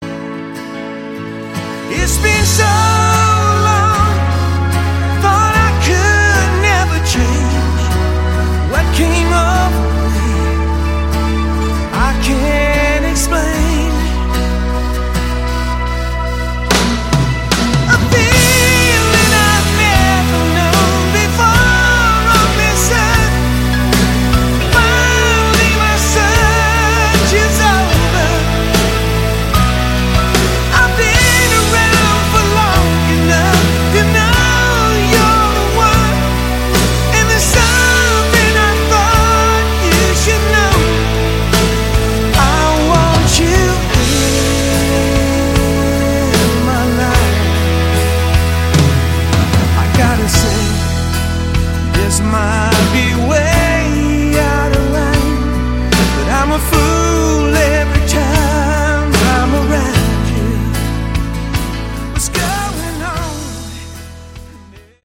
Category: AOR
vocals
guitars, keyboards
bass
drums